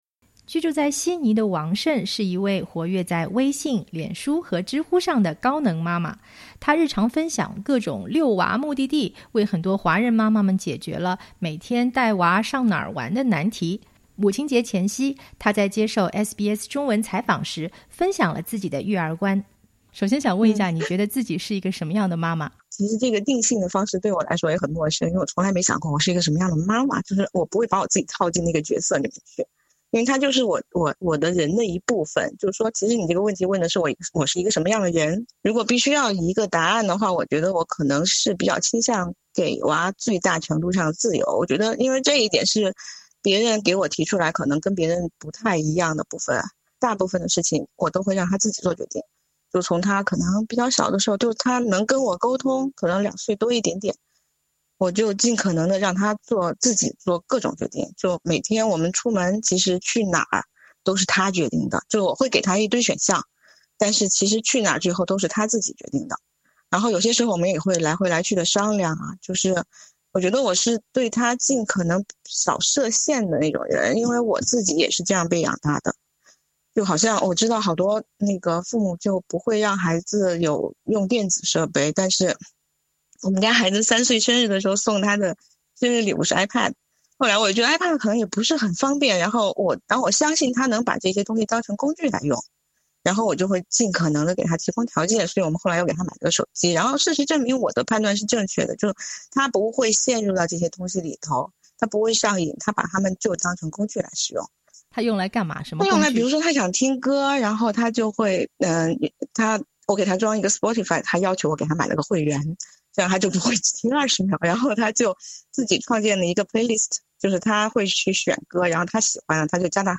母亲节前夕，她在接收SBS中文采访时分享了自己的育儿观。